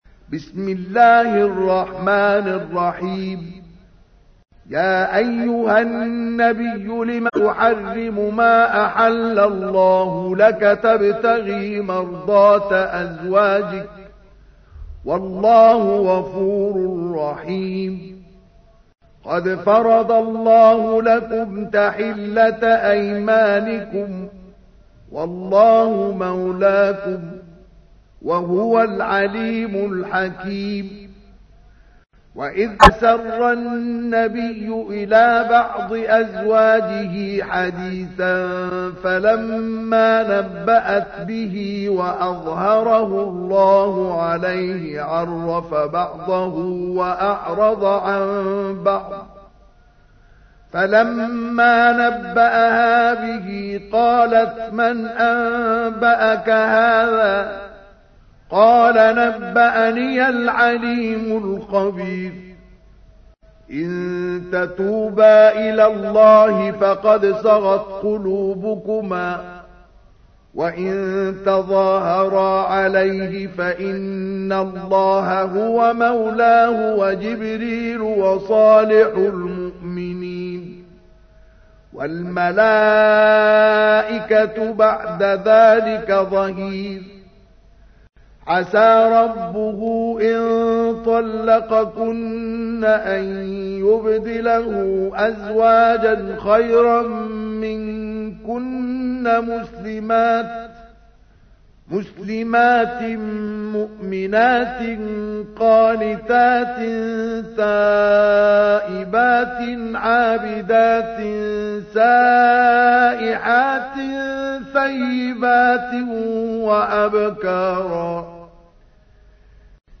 تحميل : 66. سورة التحريم / القارئ مصطفى اسماعيل / القرآن الكريم / موقع يا حسين